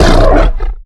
giant_hurt_2.ogg